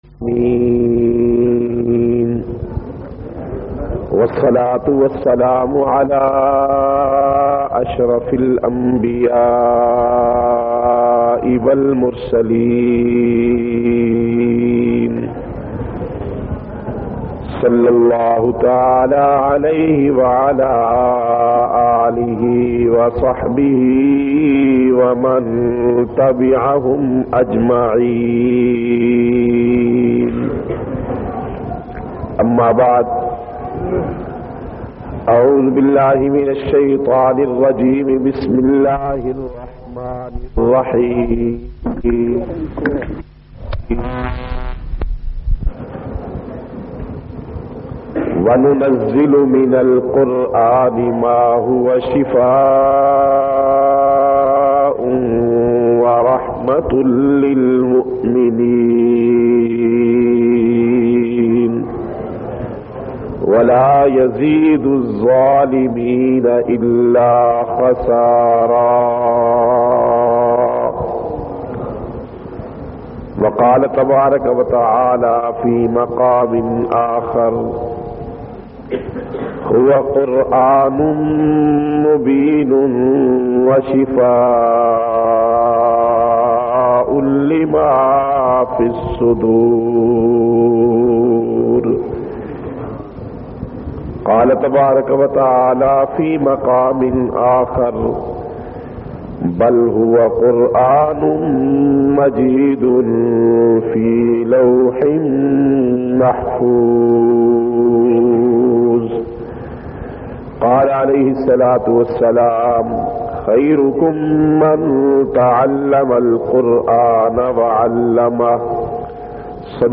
465- Salana Ijtima Madrasa Madina Tul Uloom.mp3